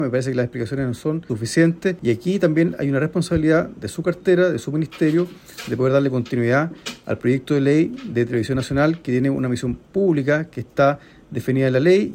En tanto, el diputado del Partido Comunista, Luis Cuello, apuntó a que las justificaciones entregadas por la ausencia de la ministra Sedini en la Comisión de Cultura no fueron suficientes.